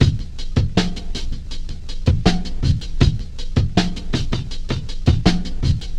Boredom Drum Break 80bpm.wav